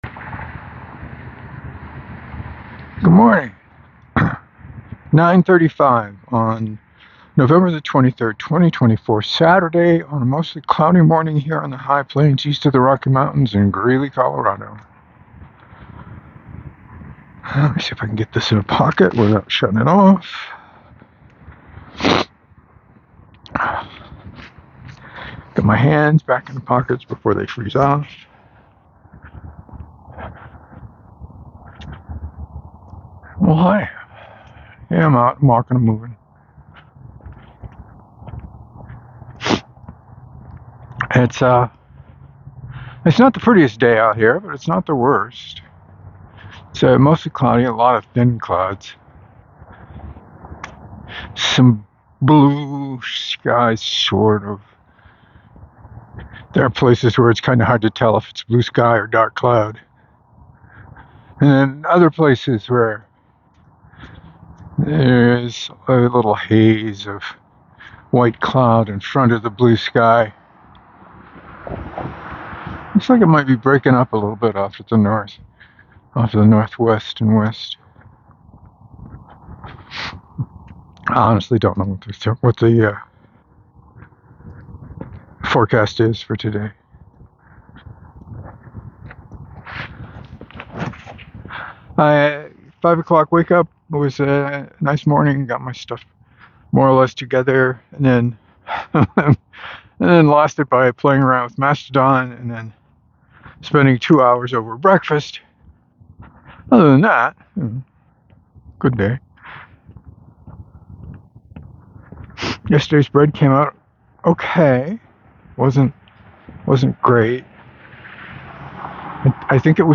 But not a bad day for a walk. Talked about the bread, X4, Wandering Inn, and the landscape. Interesting encounter with a young man on a bicycle reciting something in what sounded like Arabic to me.